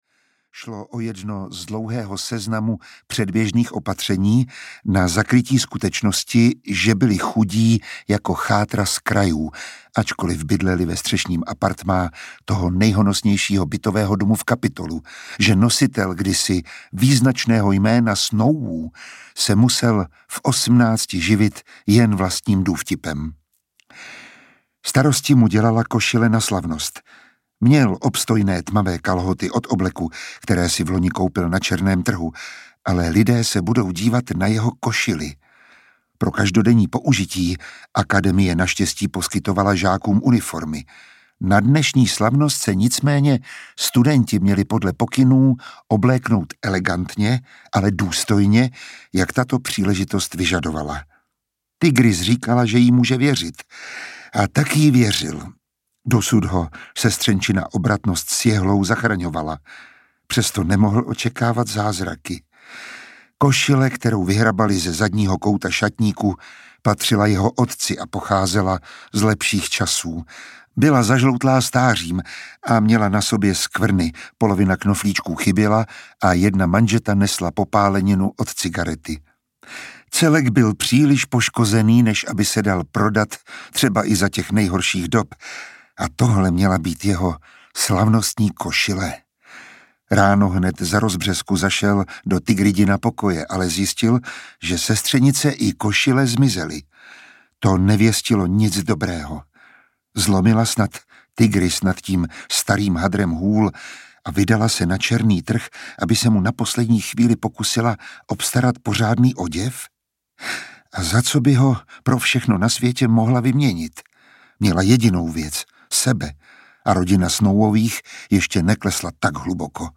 Audio knihaBalada o ptácích a hadech
Ukázka z knihy
• InterpretMiroslav Táborský